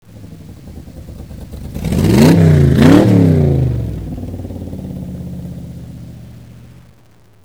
Chevy V8 Sound
chevyv8.wav